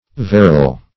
verrel - definition of verrel - synonyms, pronunciation, spelling from Free Dictionary Search Result for " verrel" : The Collaborative International Dictionary of English v.0.48: Verrel \Ver"rel\, n. See Ferrule .